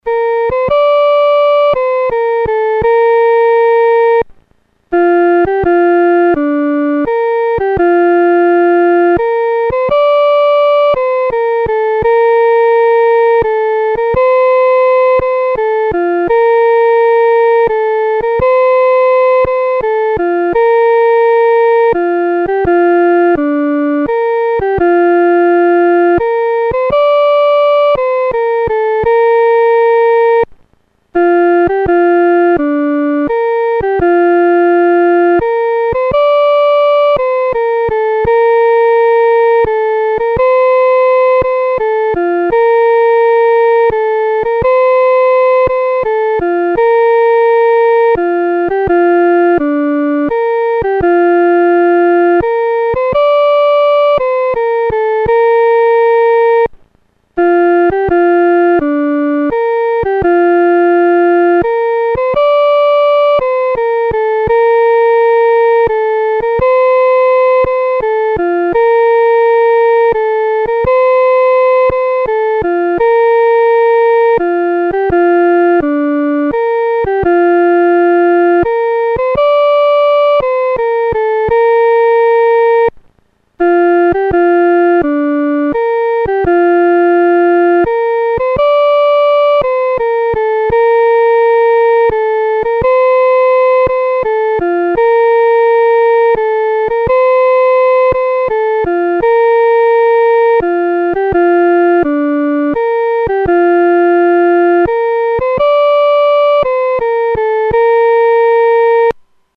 独奏（第一声）
万古磐石-独奏（第一声）.mp3